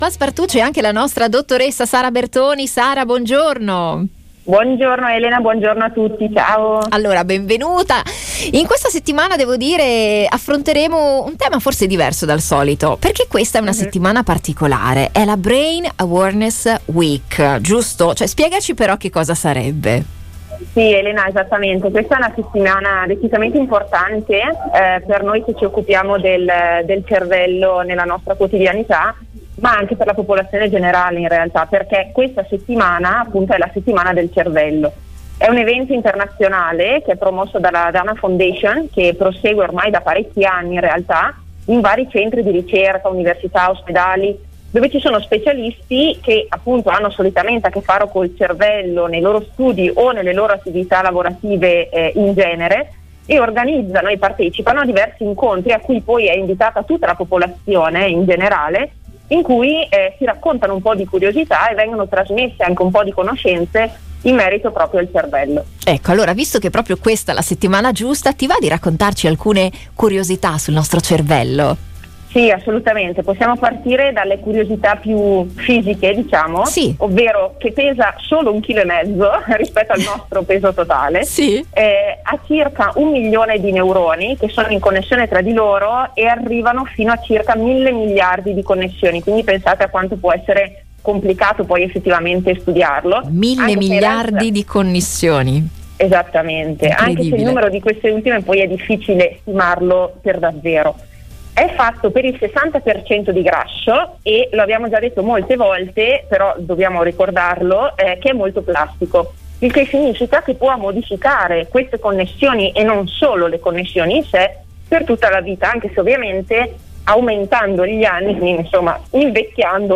Ne abbiamo parlato con la dottoressa in psicologia